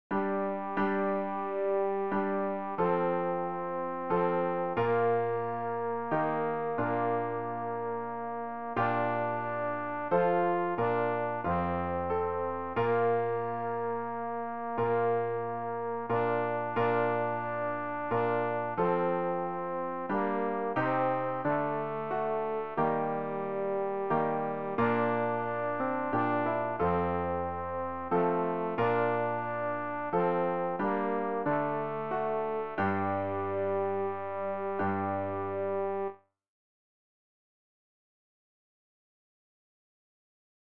tenor-rg-386-ein-kind-geborn-zu-betlehem.mp3